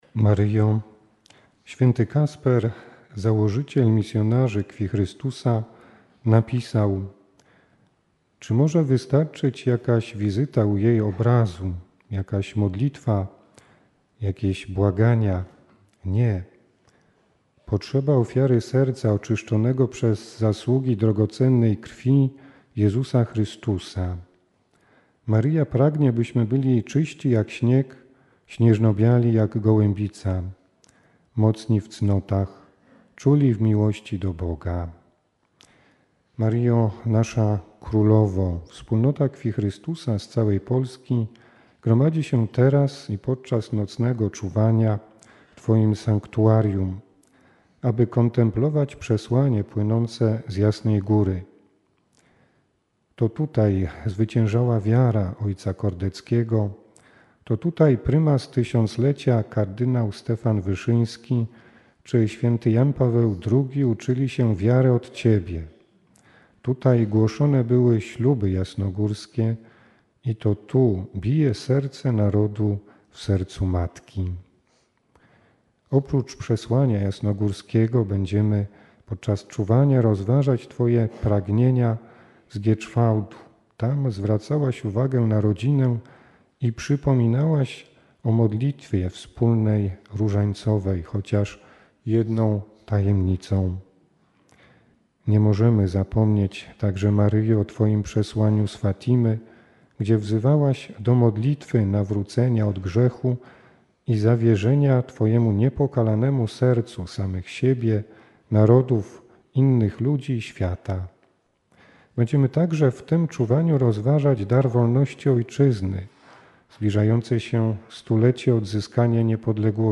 Ogólnopolskie Czuwanie Wspólnoty Krwi Chrystusa
Rozważanie Jasna Góra 09.12.2017 r.